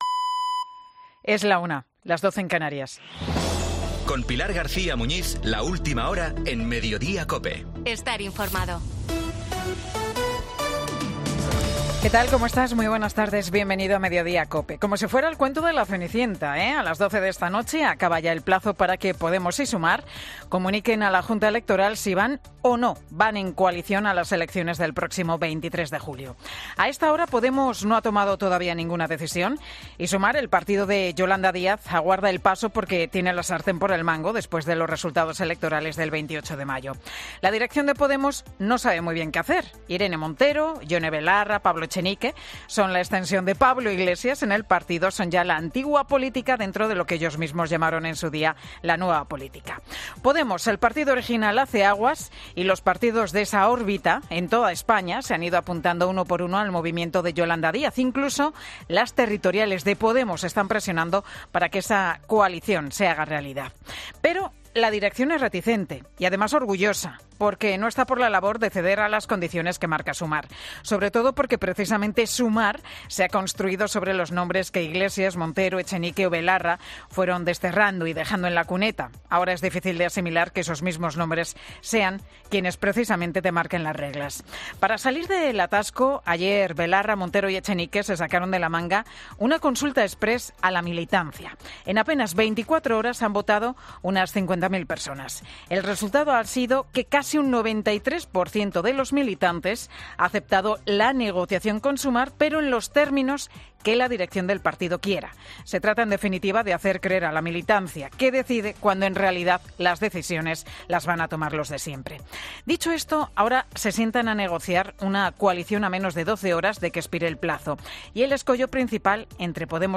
Escucha el monólogo de Pilar García Muñiz del viernes 9 de junio en Mediodía COPE